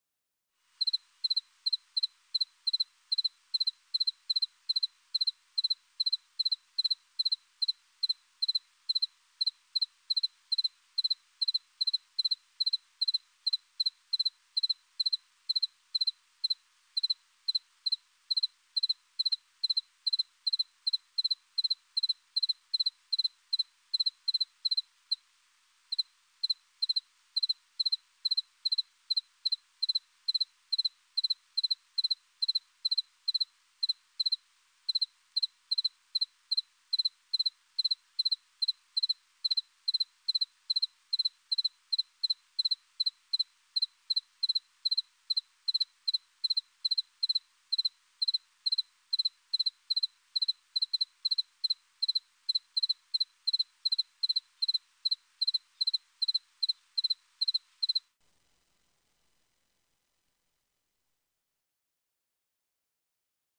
Husfårekylling (Acheta domesticus)
Lyt til husfårekyllingen.
husfårekylling.mp3